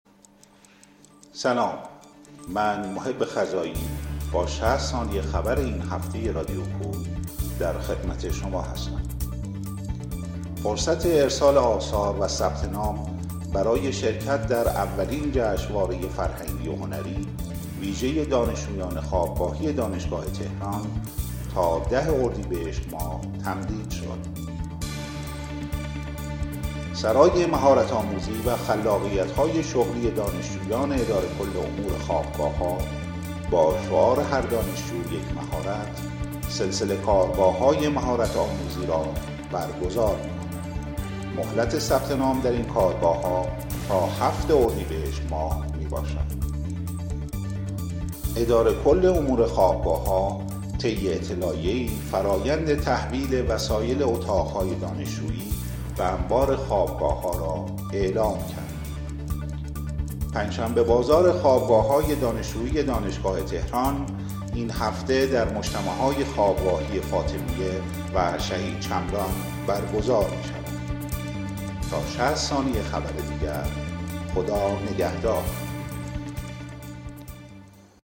اخبار ۶۰ ثانیه‌ای [۶ اردیبهشت ۱۴۰۲]